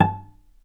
vc_pz-A5-mf.AIF